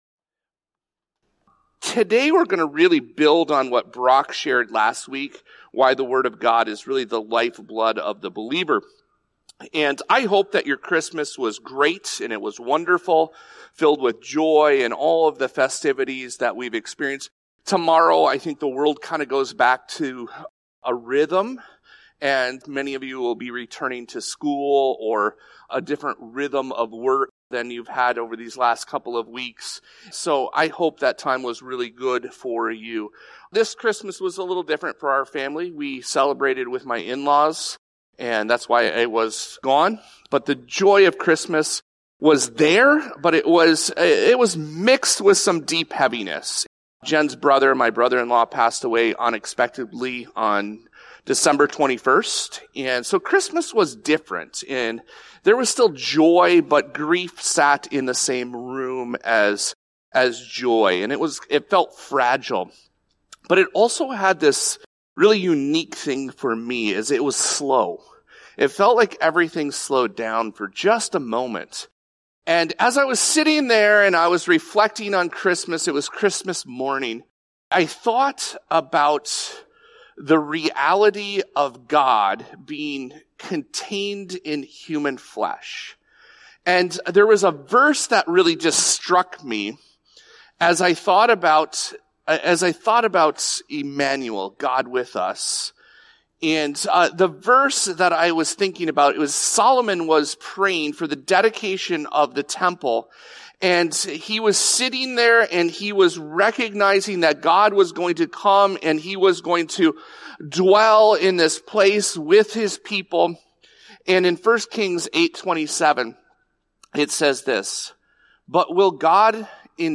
A message between sermon series, by a guest speaker, or for a special event or holiday.